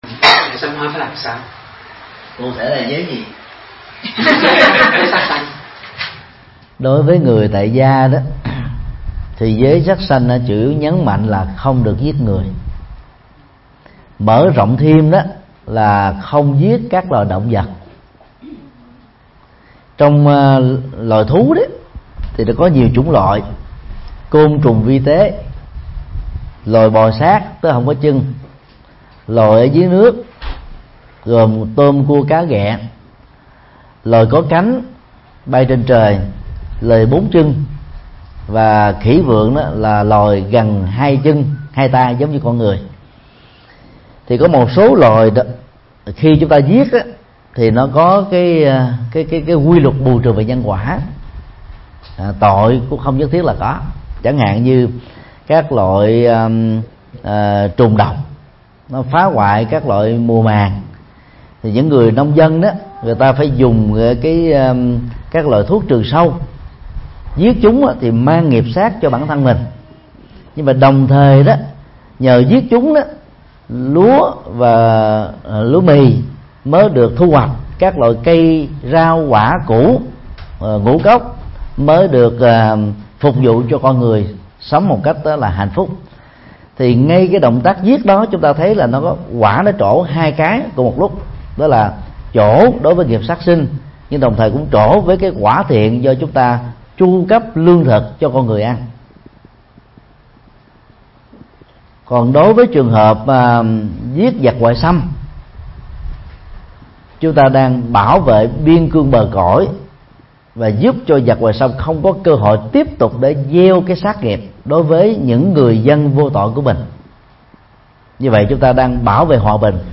Vấn đáp